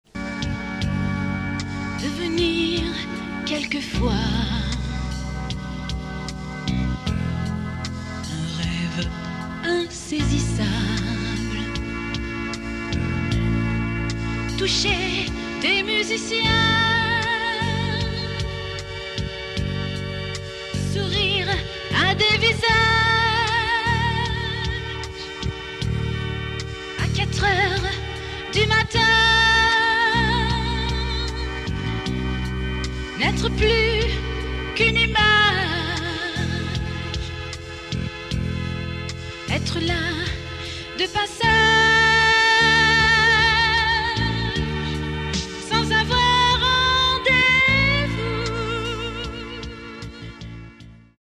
EXTRAIT SLOWS